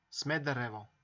Summary Description Sr-smederevo.ogg English: Serbian pronunciation of Smederevo .
Sr-smederevo.ogg